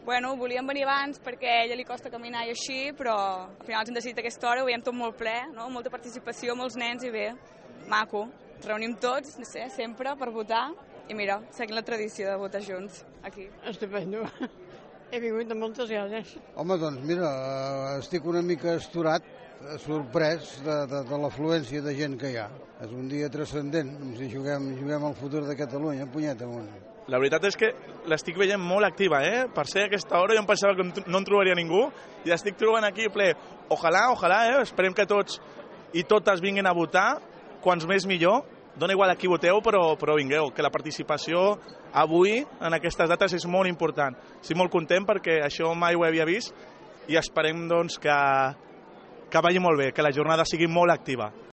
A les Escoles Freta, hem parlat amb els més matiners, alguns d’ells ho feien per primera vegada i altres s’han sentit, aquest cop, més cridats que en anteriors ocasions.
I migdia de cues intenses a la Fàbrica Llobet-Guri per anar a votar. Molta gent coincidia en opinar que mai abans en unes eleccions al Parlament s’havia vist tanta participació ni tanta gent acostant-se a les urnes.